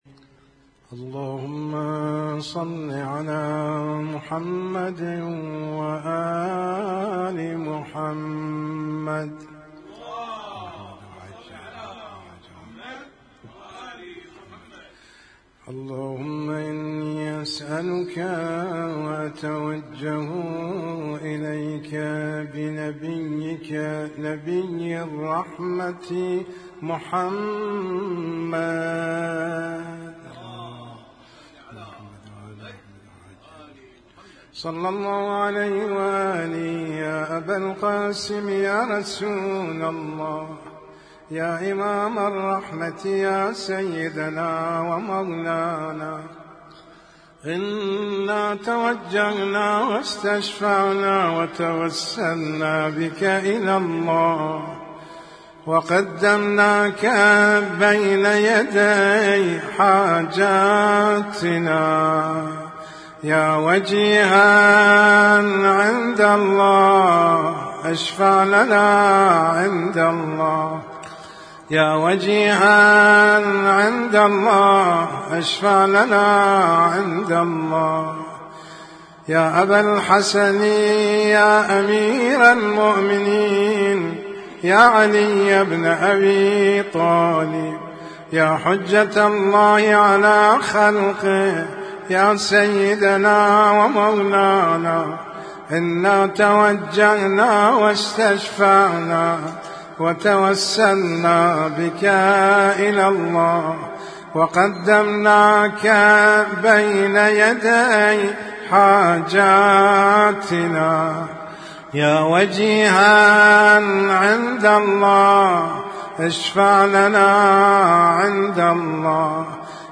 اسم التصنيف: المـكتبة الصــوتيه >> الادعية >> الادعية المتنوعة